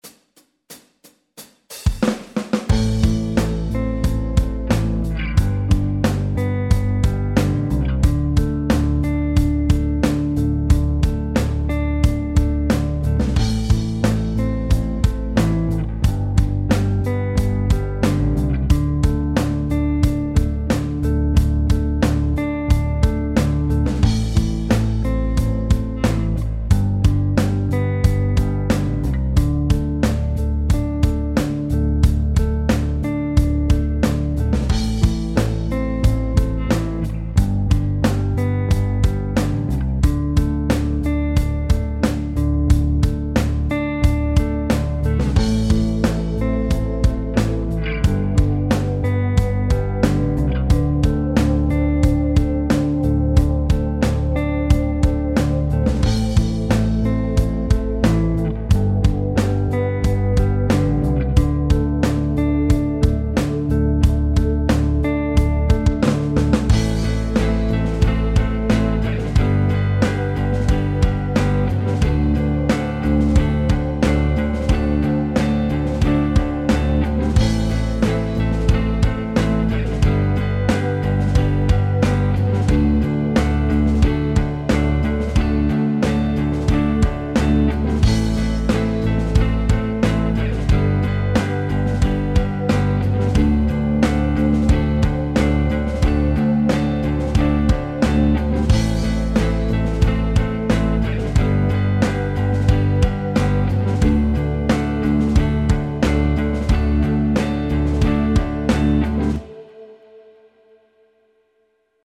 Blues Backing Track in Am